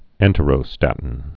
(ĕntə-rō-stătn)